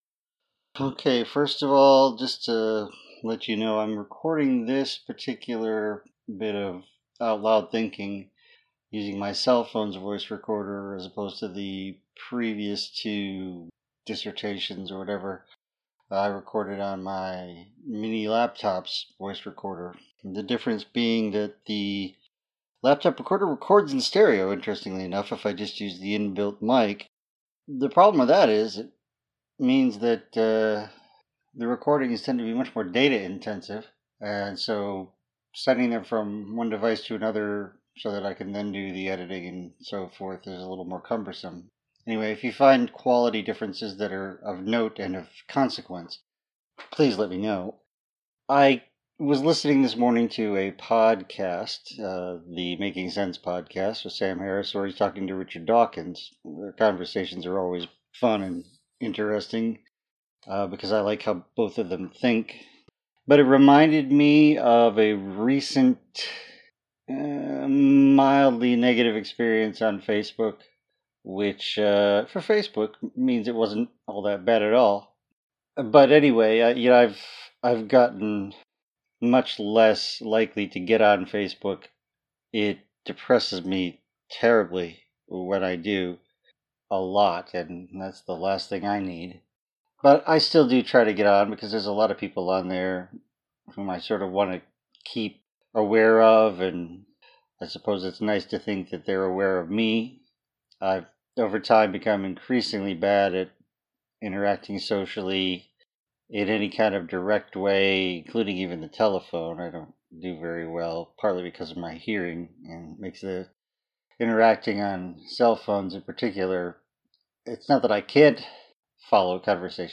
It may be a bit meandering, since it was firmly “off-the-cuff”, but hopefully it’s interesting enough to hold your attention for about twenty minutes.